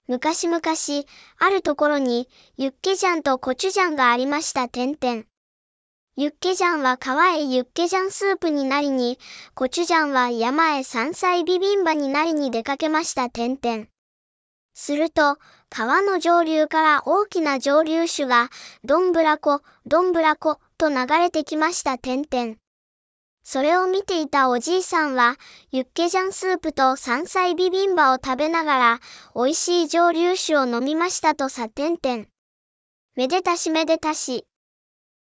AI音声読み上げ
こちらAI音声読み上げは、テキスト文章をAI音声が読み上げてくれる機能です。
「。」を「てんてん」と読むところ、「～」を伸ばし棒として認識しないところを抜きにすれば完璧じゃないでしょうか。